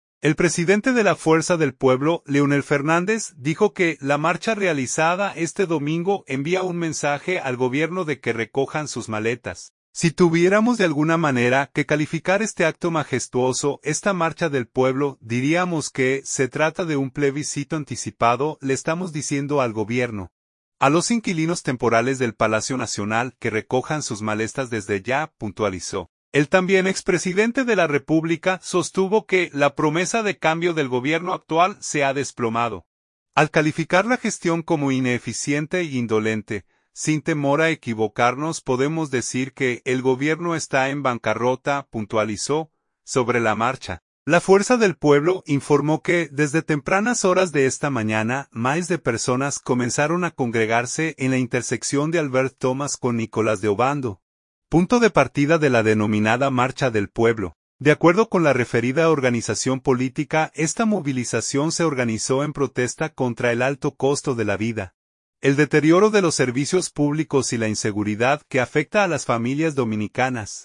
El presidente de la Fuerza del Pueblo, Leonel Fernández, dijo que la marcha realizada este domingo envía un mensaje al Gobierno de que recojan sus maletas.